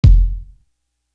Trinitron Kick.wav